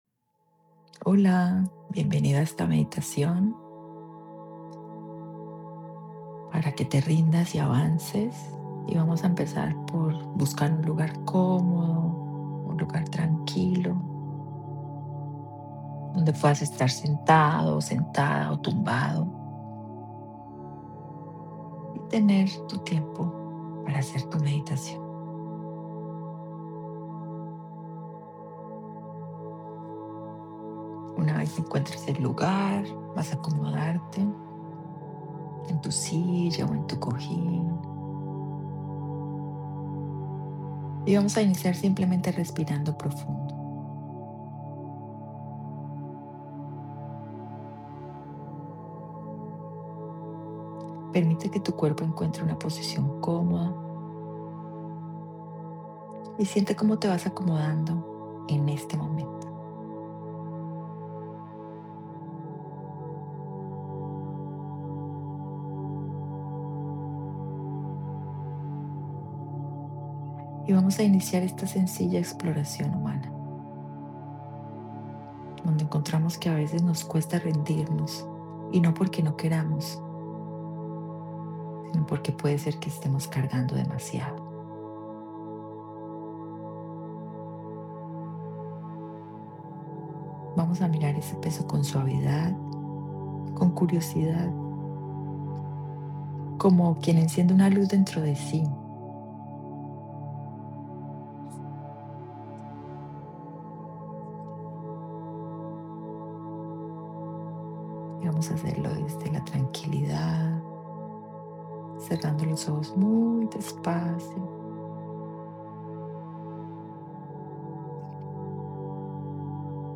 Meditación guiada para soltar emociones, liberar cargas inconscientes y transformar tu perspectiva desde un enfoque psicoterapéutico.